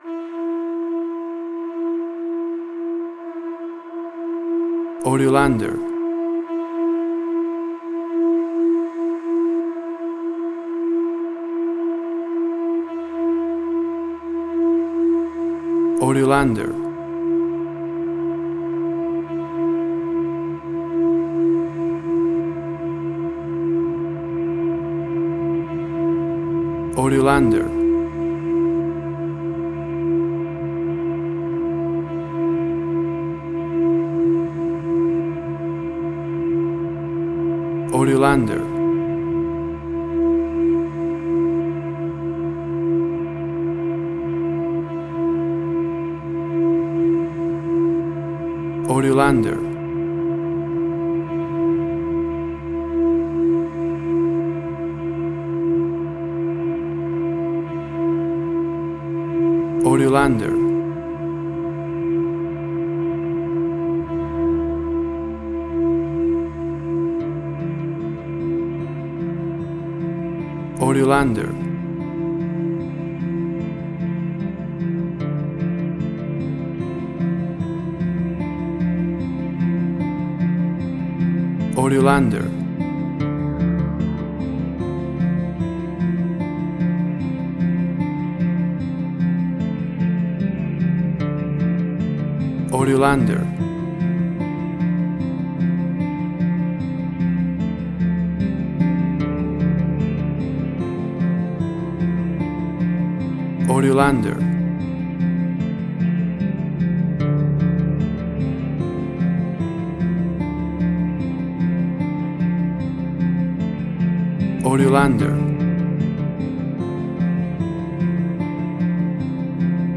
Suspense, Drama, Quirky, Emotional.
Tempo (BPM): 150